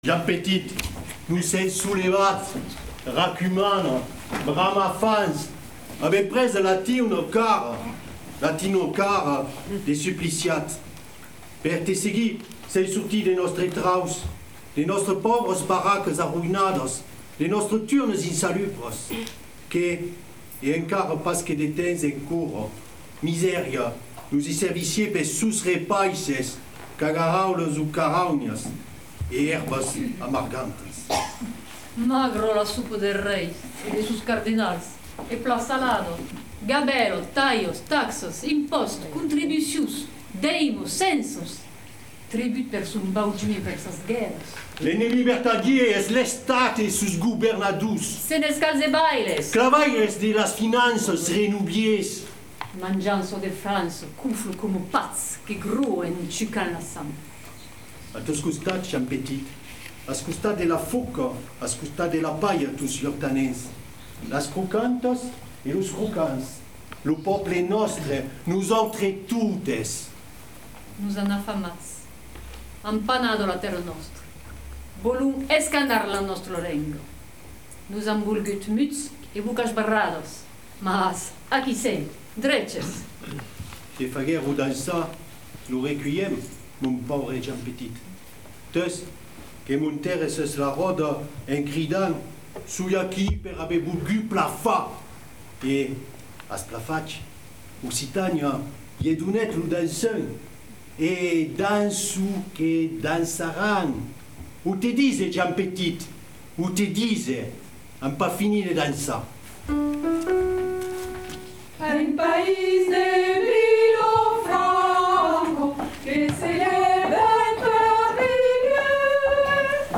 Lo tablèu dedicat a Joan Petit, extrach de l’espectacle Resisténcias que siaguèt donat per la corala Nadalenca fa quauques annadas.
par Nadalenca